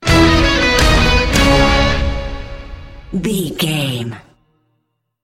Ionian/Major
C#
horns
drums
electric guitar
synthesiser
synth effects
driving drum beat